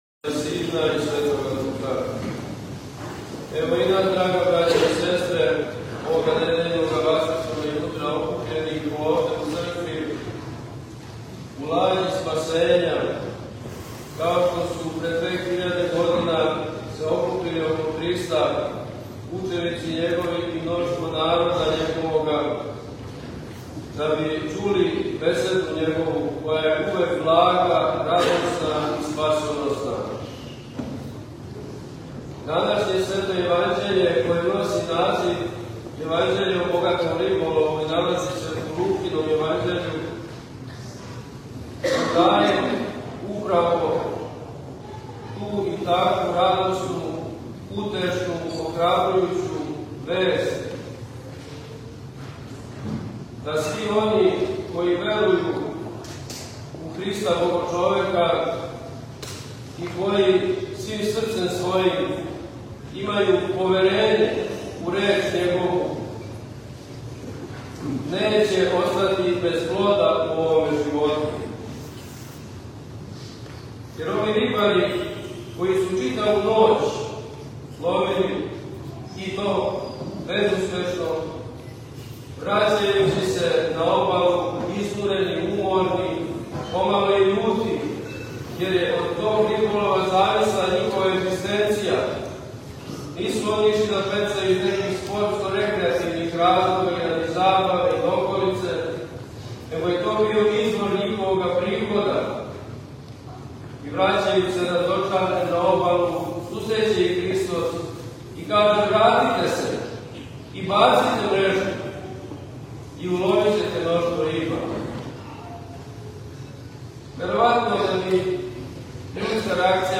Бесједа Владике западноевропског г. Јустина у храму Светог Саве у Паризу
У прилогу емитујемо бесједу Његовог Преосвештенства епископа западноевропског г. Јустина изговорену на литургији у 18. недјељу по Педесетници, 16. октобра […]